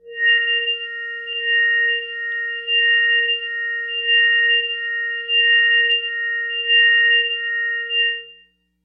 描述：通过Modular Sample从模拟合成器采样的单音。
标签： ASharp5 MIDI音符-82 Korg的-Z1 合成器 单票据 多重采样
声道立体声